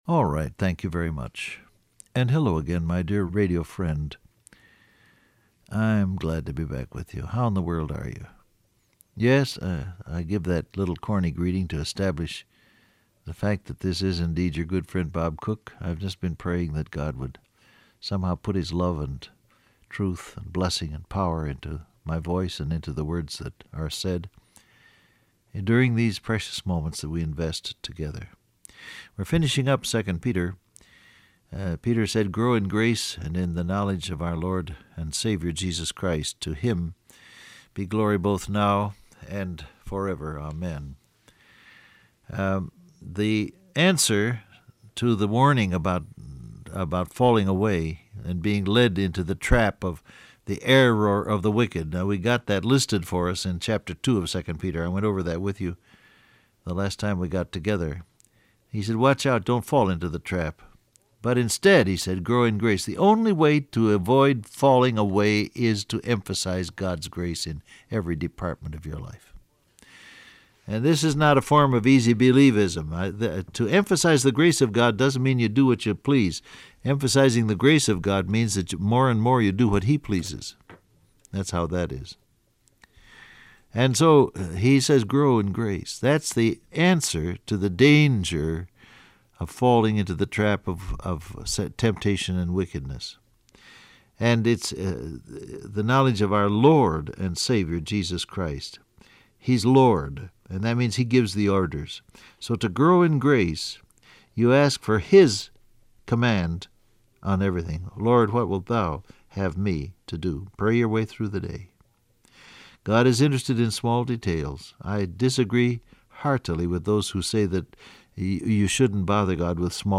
Alright, thank you very much and hello again my dear radio friend.